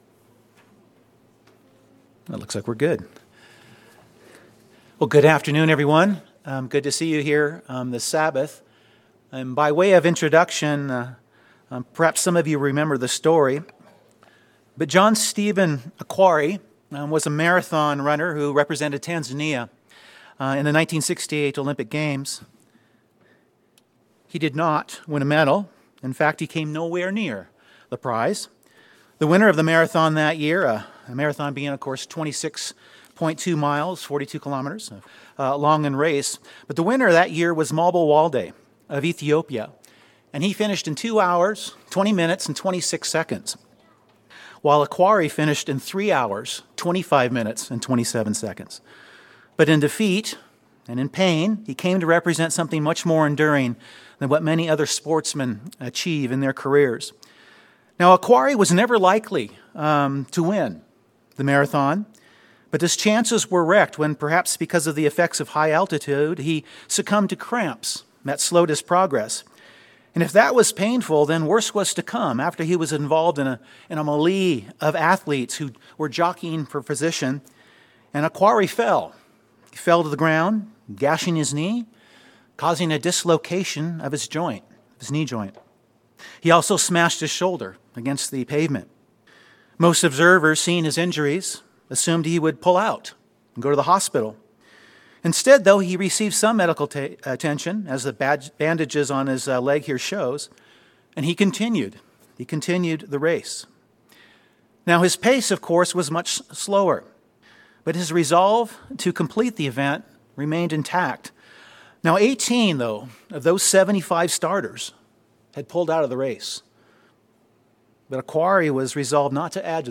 This sermon focuses on our need for endurance (Heb. 10:36), how we run the race of endurance (Heb. 12:1-3), what makes it possible, and its necessary part of our pursuit of the kingdom of God.